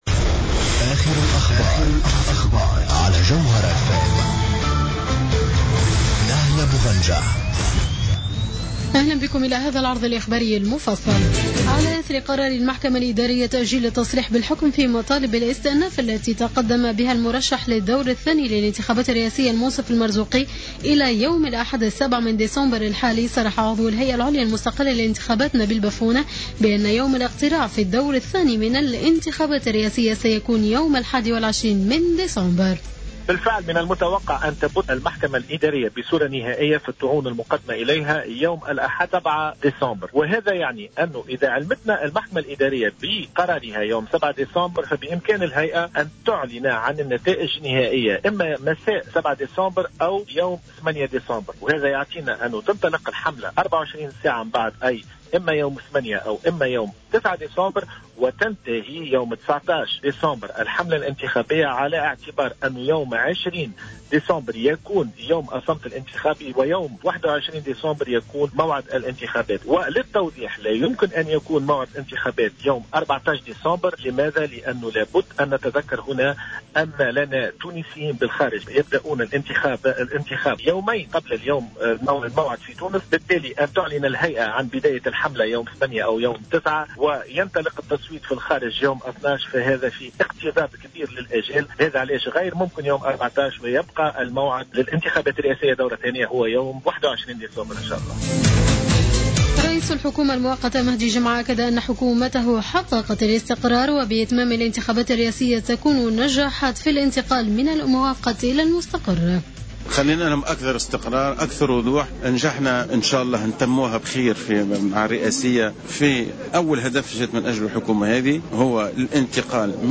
نشرة أخبار منتصف الليل ليوم السبت 06-12-14